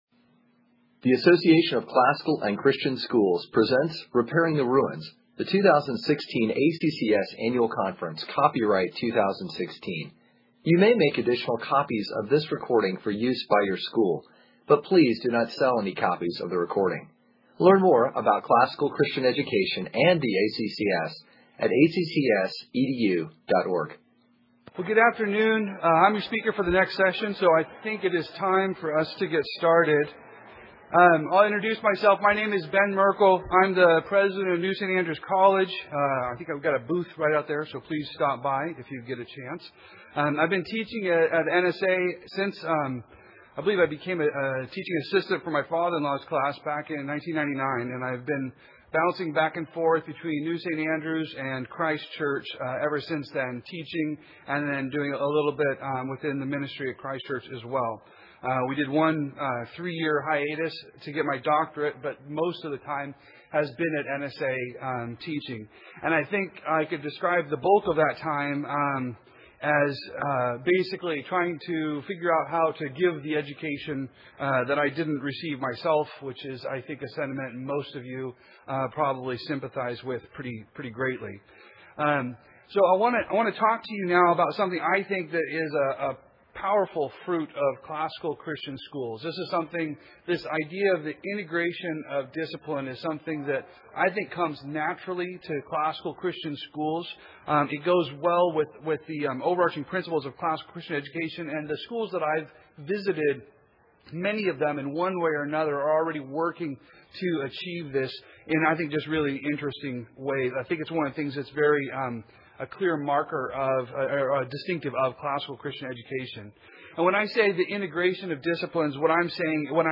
2016 Workshop Talk | 0:58:37 | General Classroom